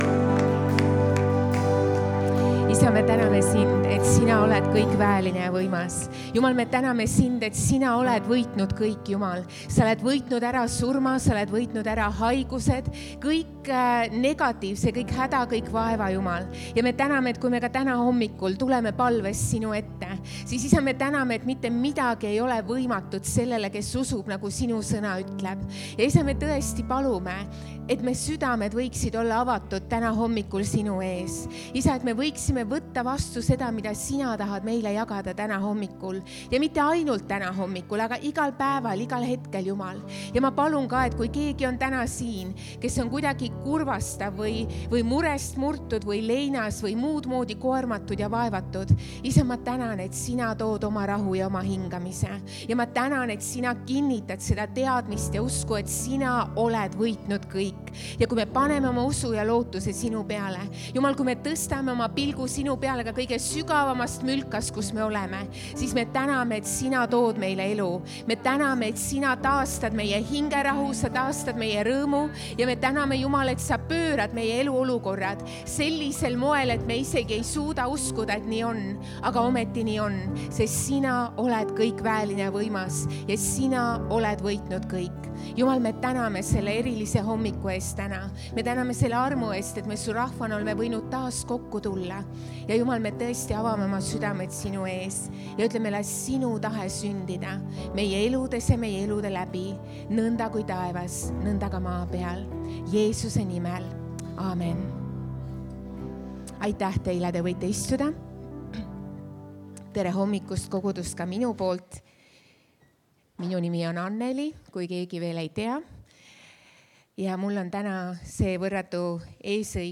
Jutlused - EKNK Toompea kogudus